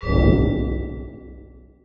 Isoulated/Assets/Audio/Effects/MenuClick.wav at main
MenuClick.wav